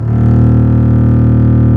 Index of /90_sSampleCDs/Roland L-CD702/VOL-1/STR_Cb Bowed/STR_Cb3 Arco nv
STR DBLBAS00.wav